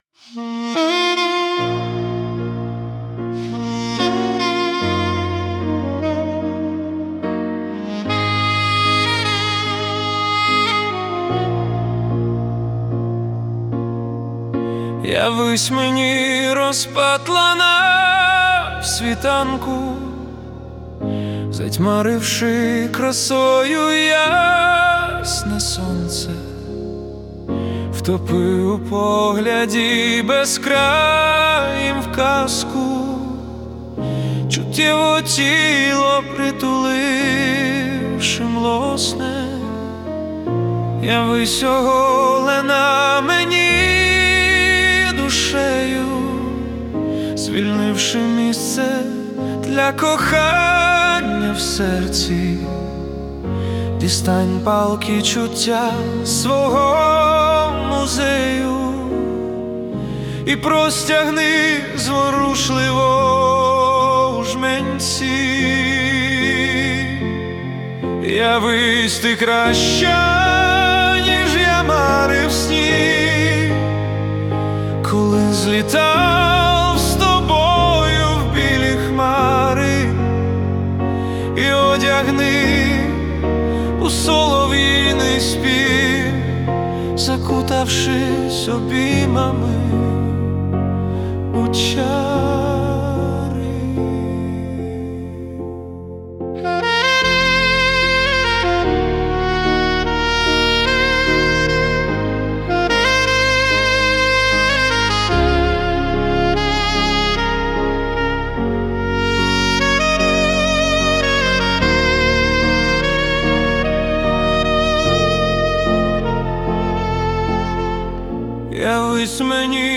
Музика і вокал ШІ - SUNO AI
СТИЛЬОВІ ЖАНРИ: Ліричний
ВИД ТВОРУ: Пісня